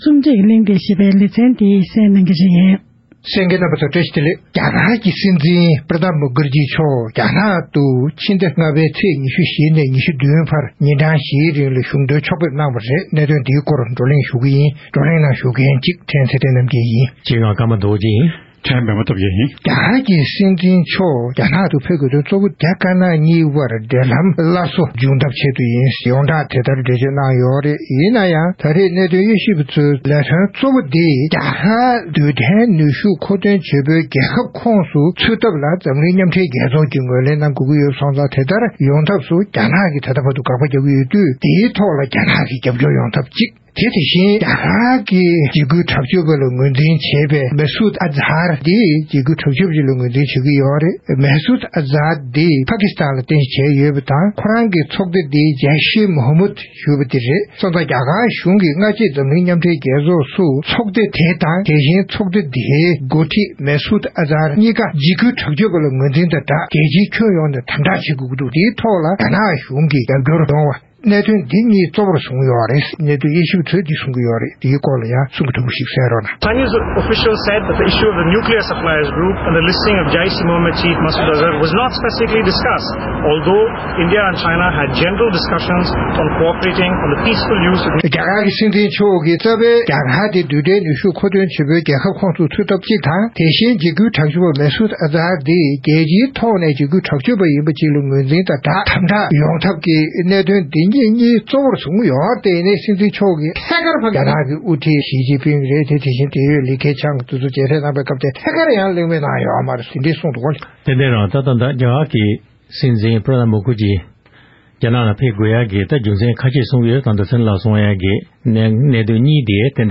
༄༅༎ཐེངས་འདིའི་རྩོམ་སྒྲིག་པའི་གླེང་སྟེགས་ཞེས་པའི་ལེ་ཚན་གྱི་ནང་དུ་རྒྱ་གར་གྱི་སྲིད་འཛིན་Pranab Mukherjeeམཆོག་བདུན་ཕྲག་སྔོན་མར་རྒྱ་ནག་ཏུ་གཞོན་དོན་ཕྱོགས་ཕེབས་གནང་སྟེ་རྒྱ་དཀར་ནག་གཉིས་ཀྱི་འབྲེལ་ལམ་སླར་གསོའི་སྐུ་དོན་ཇི་གནང་སོགས་ཀྱི་སྐོར་རྩོམ་སྒྲིག་འགན་འཛིན་རྣམ་པས་གླེང་མོལ་གནང་བར་གསན་རོགས་ཞུ༎